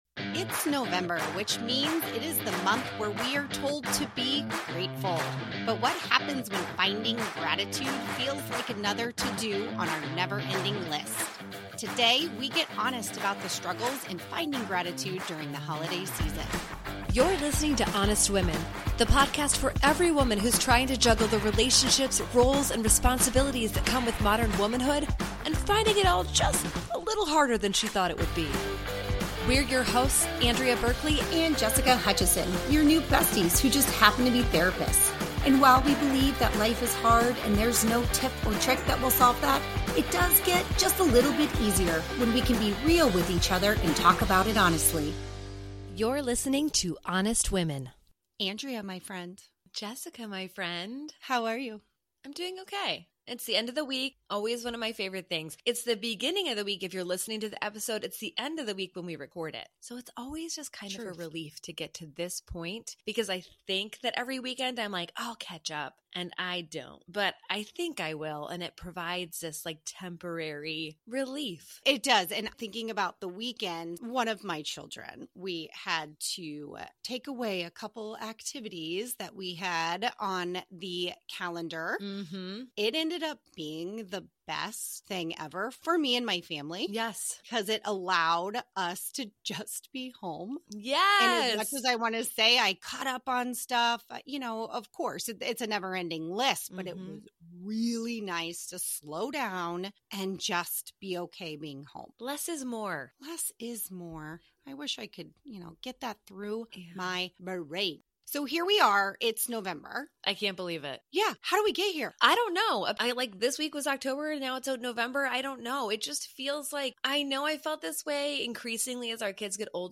Thanks for joining us in this candid conversation.